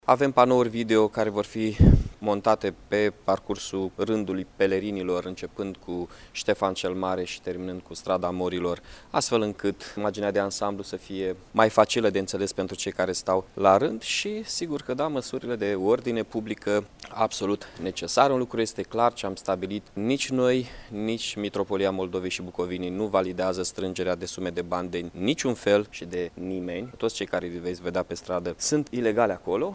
Cu ocazia Sărbătorilor Iaşului şi a hramului Sfintei Cuvioase Parascheva, pietonalul va fi extins de la Prefectură până la clădirea Casei Modei din Iaşi, a afirmat astăzi, primarul Mihai Chirica.